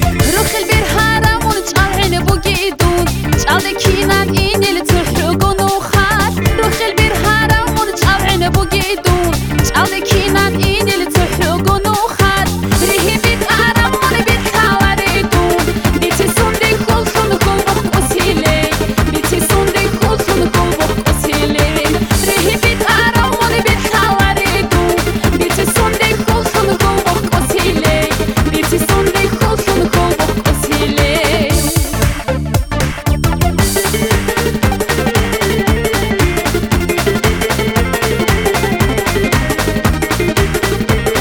• Качество: 320, Stereo
веселые
кавказские
аварские
кавказская музыка
Аварская кавказская музыка.